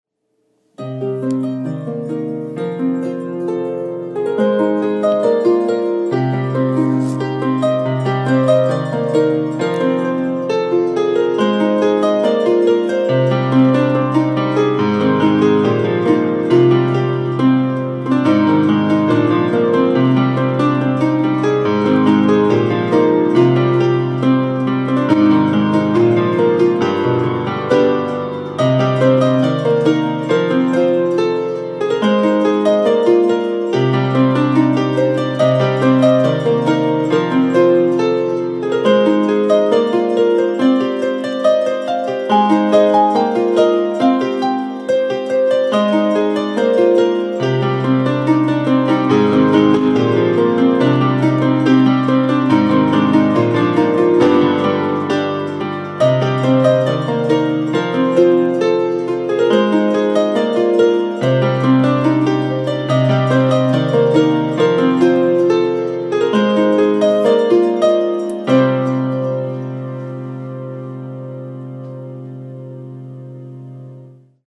Index of /music/pianoSketches